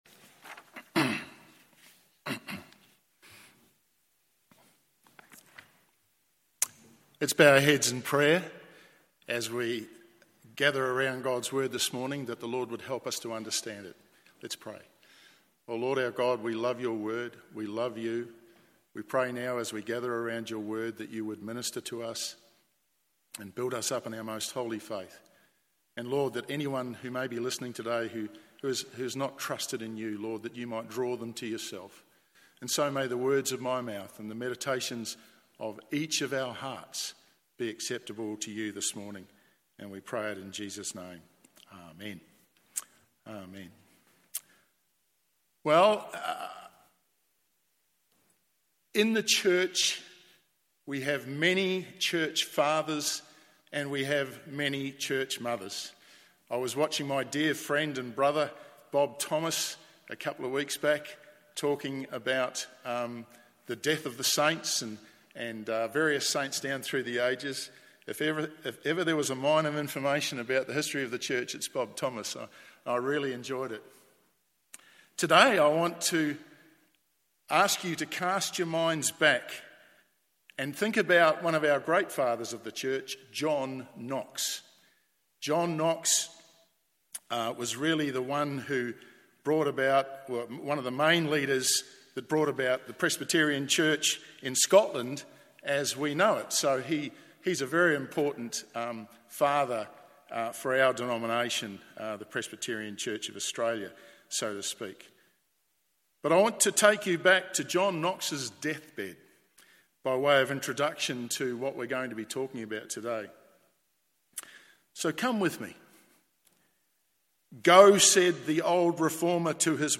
MORNING SERVICE John 17:1-5 That Jesus would give eternal life to as many as the Father has given him…